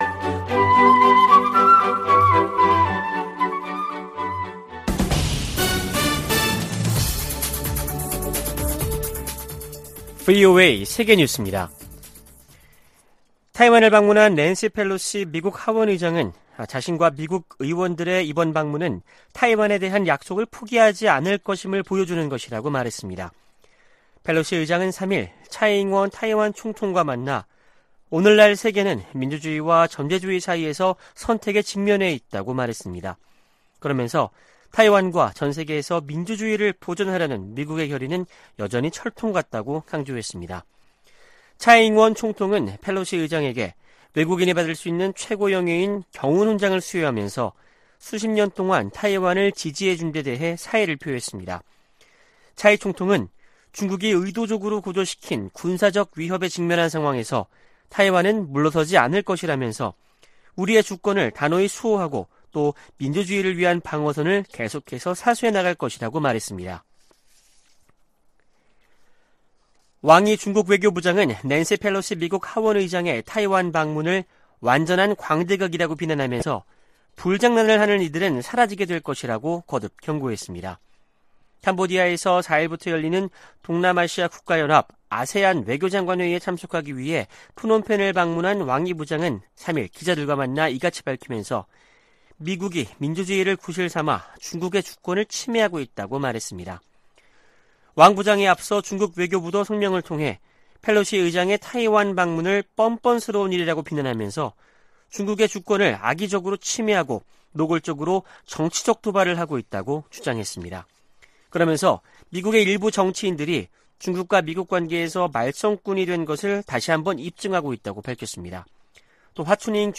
VOA 한국어 아침 뉴스 프로그램 '워싱턴 뉴스 광장' 2022년 8월 4일 방송입니다. 미 국무부는 ‘확인된 정보’가 없다는 중국 측 주장에 대해, 북한이 7차 핵실험을 준비하고 있는 것으로 평가한다고 밝혔습니다.